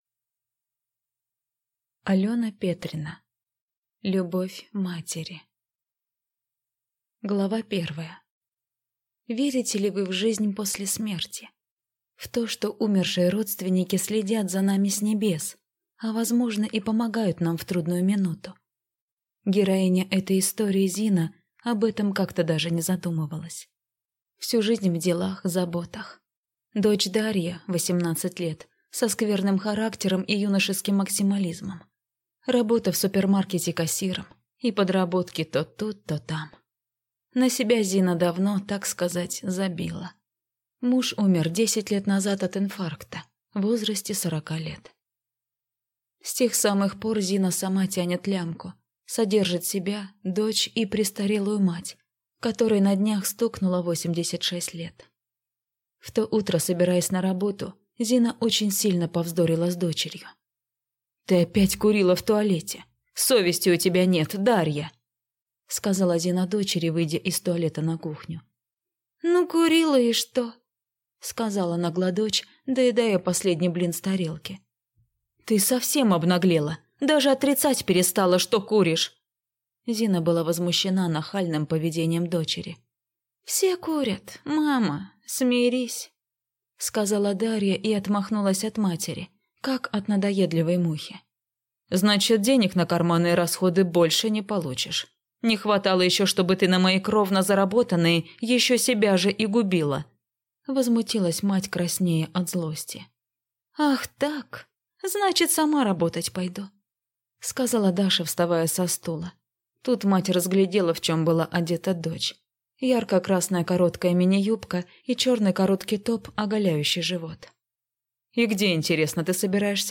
Аудиокнига Любовь матери | Библиотека аудиокниг